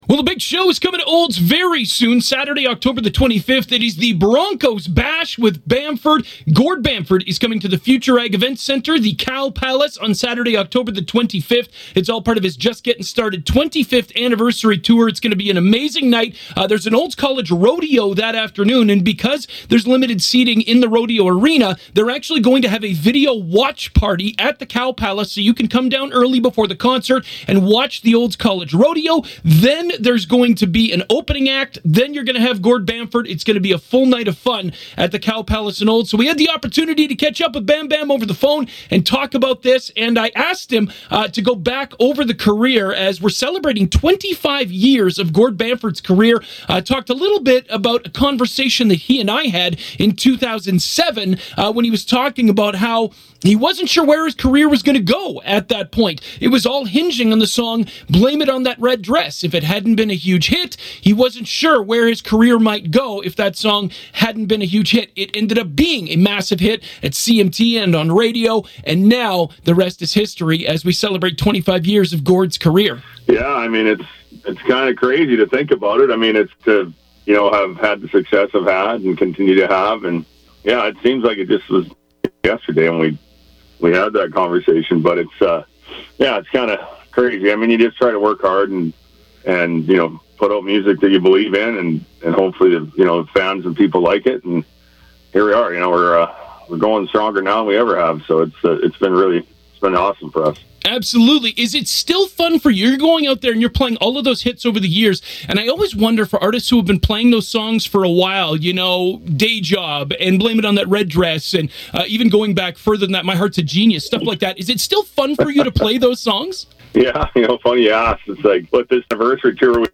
Listen to 96.5 The Ranch’s Community Hotline conversation with Gord Bamford.